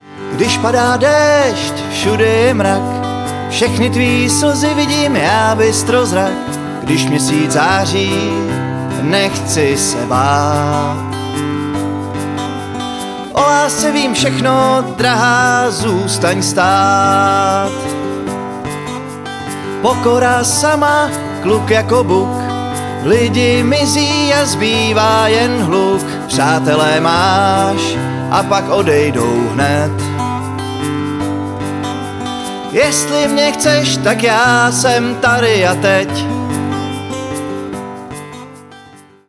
foukací harmonika
banjo
klavesy